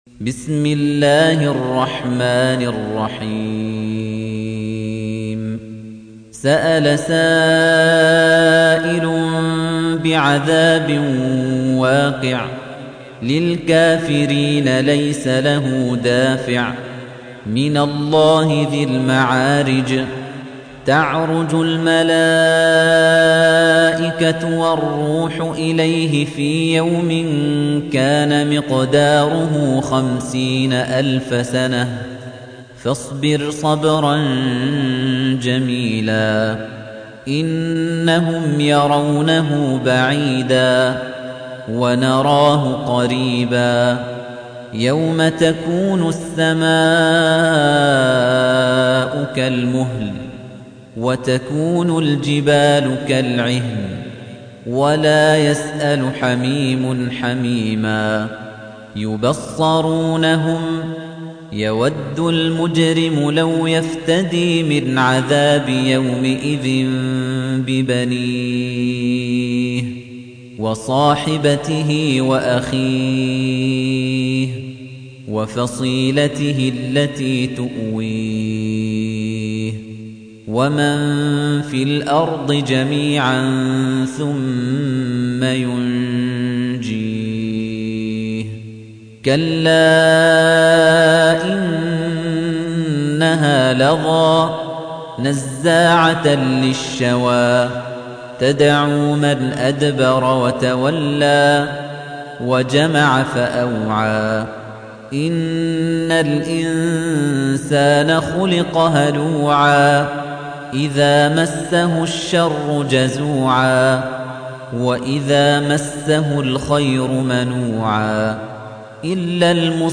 تحميل : 70. سورة المعارج / القارئ خليفة الطنيجي / القرآن الكريم / موقع يا حسين